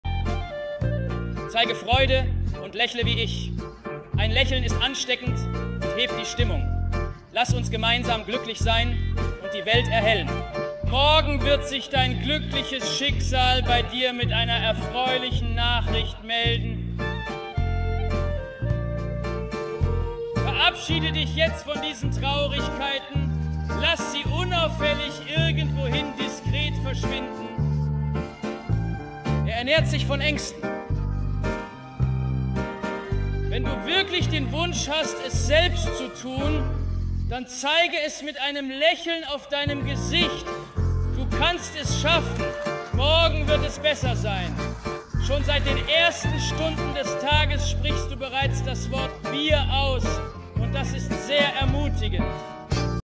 Нейросеть перевела польскую песню на немецкий